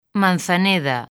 Transcripción fonética
manθaˈneða̝